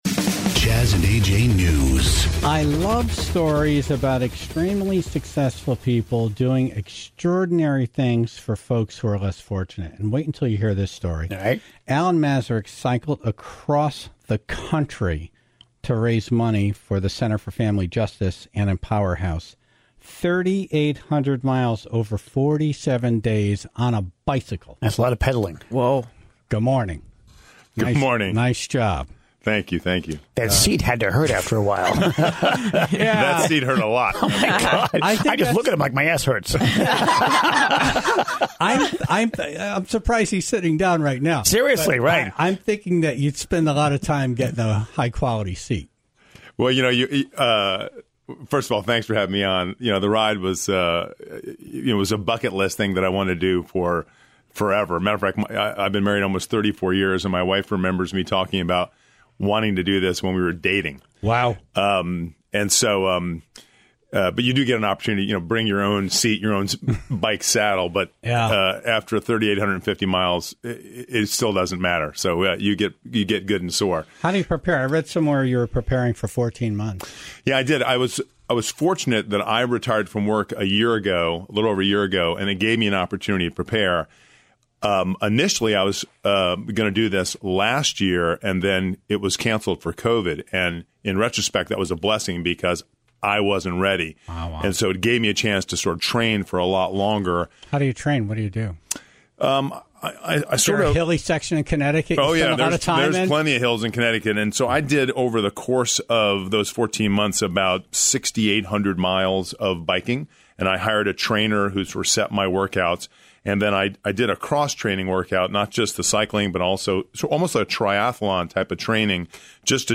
come in studio